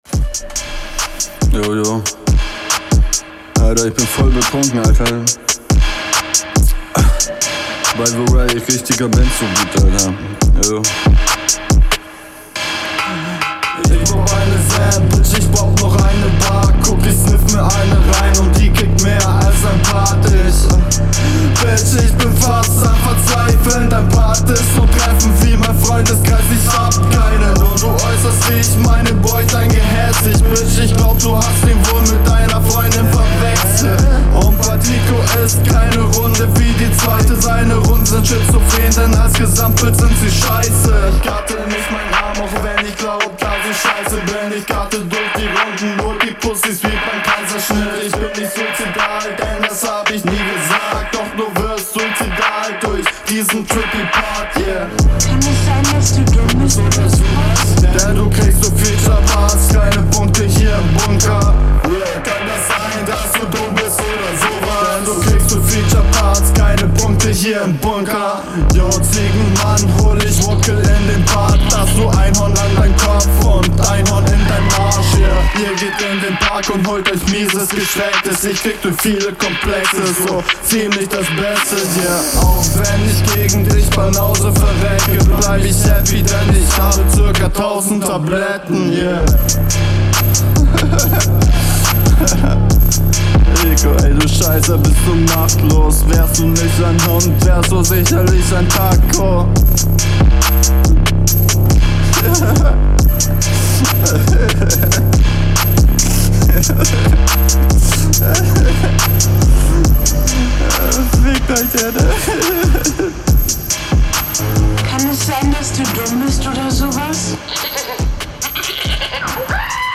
Hier versteht man zwar auch nicht alles, aber immer noch mehr als in der HR2.
Hahah drunk recorden beste. Mische wieder komplett on fire zuuu krass gemacht.